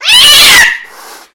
Кошка кричит и нападает (неприятный для кошек звук)